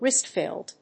• IPA(key): /ˈɹaɪsˌtɑːfəl/